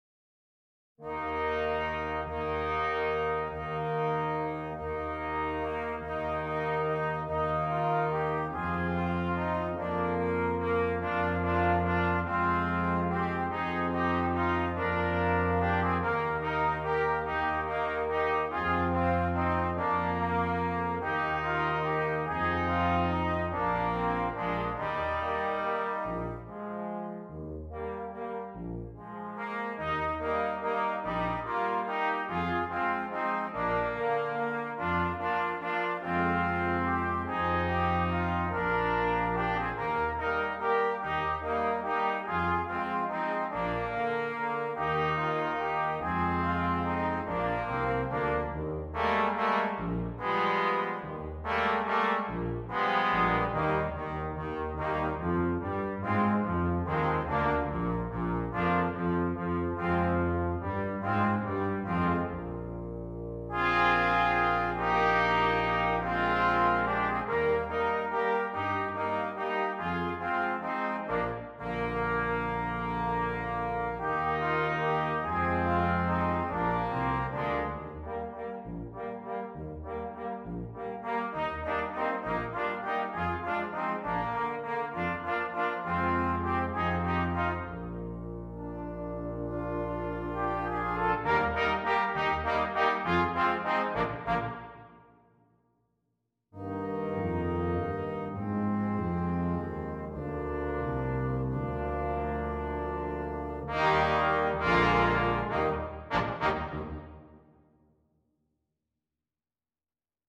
Brass Quintet
Canadian Folk Song